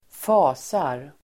Uttal: [²f'a:sar]